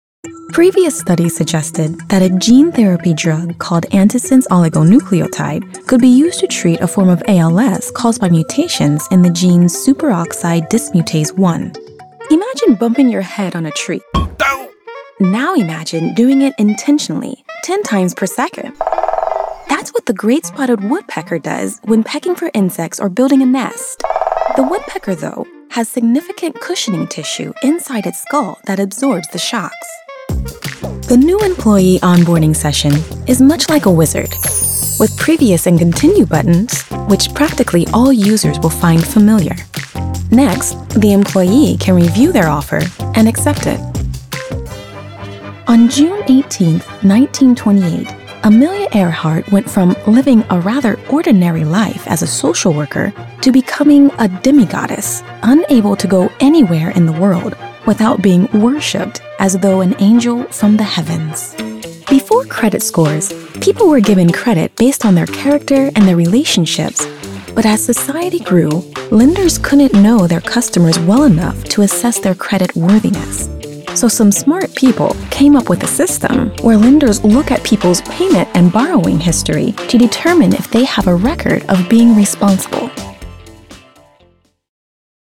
Authentic, friendly, female voice talent with quick turnaround and superb customer service
eLearning and Corporate Narration